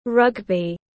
Môn bóng bầu dục tiếng anh gọi là rugby, phiên âm tiếng anh đọc là /ˈrʌɡbi/ .
Rugby /ˈrʌɡbi/
Để đọc đúng môn bóng bầu dục trong tiếng anh rất đơn giản, các bạn chỉ cần nghe phát âm chuẩn của từ rugby rồi nói theo là đọc được ngay.